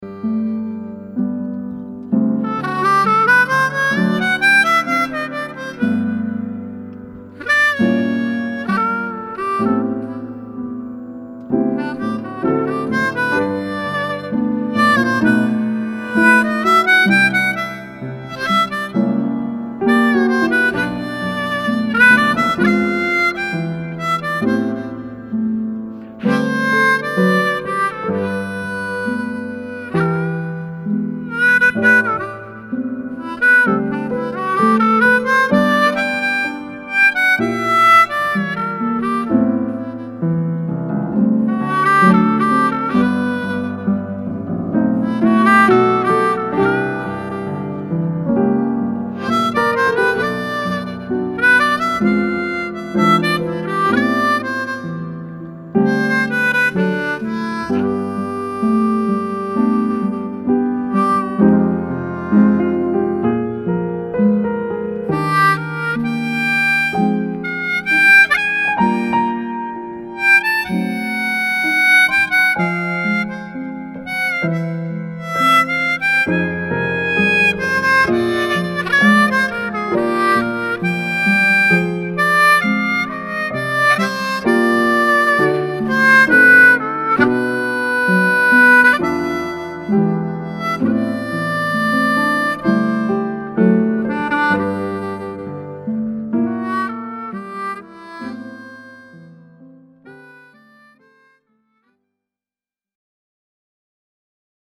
オーバーブロウ有り